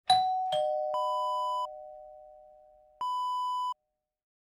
Doorbell ding-dong sound effect .wav #3
Description: Doorbell ding-dong
Properties: 48.000 kHz 24-bit Stereo
A beep sound is embedded in the audio preview file but it is not present in the high resolution downloadable wav file.
doorbell-preview-3.mp3